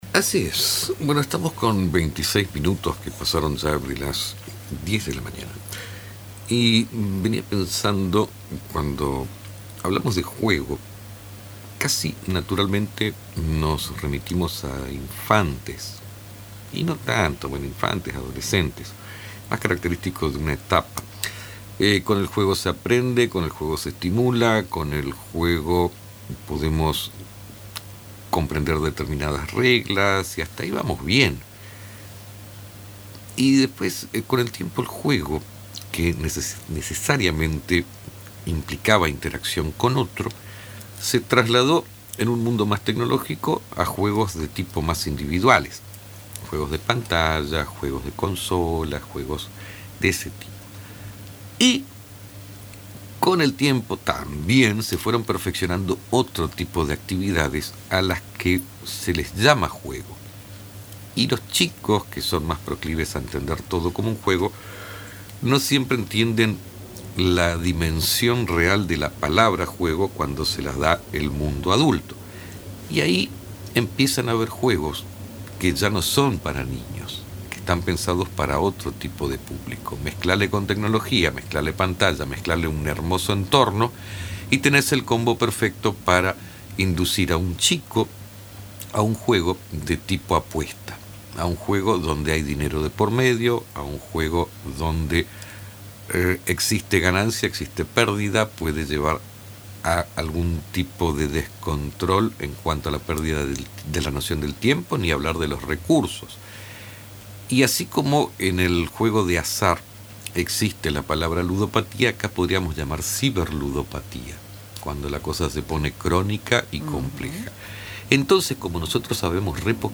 En una reciente entrevista con Radio Tupa Mbae